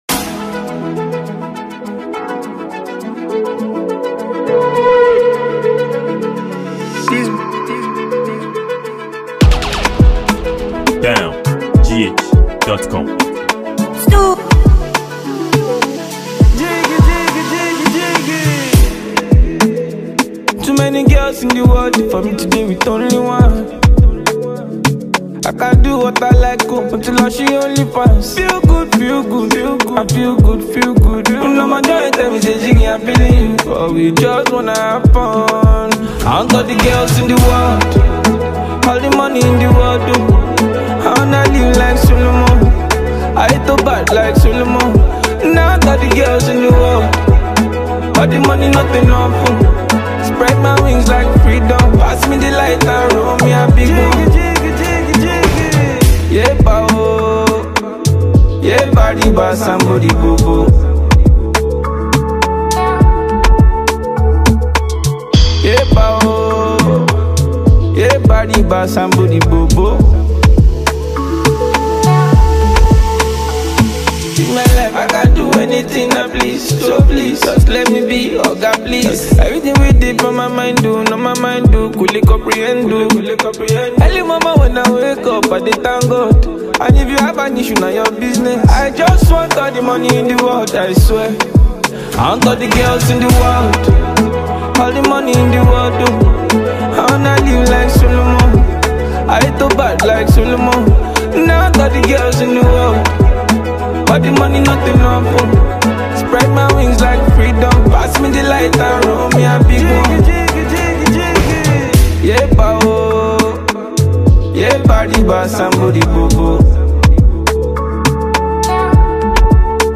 a free mp3 download afrobeat song to the public.